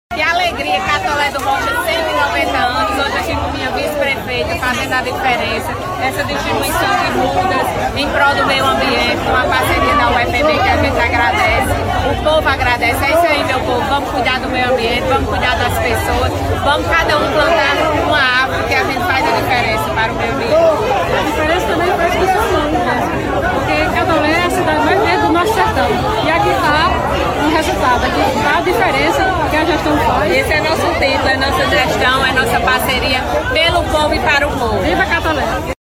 Em entrevista concedida à imprensa local, ao lado da vice-prefeita, Dra.